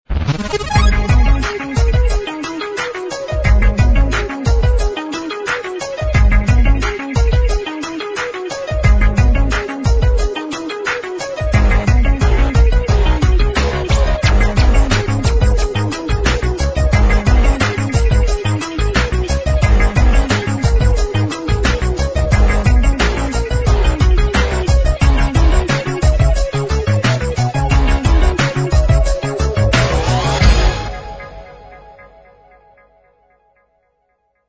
All tracks encoded in mp3 audio lo-fi quality.